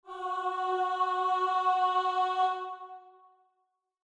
starting_note.mp3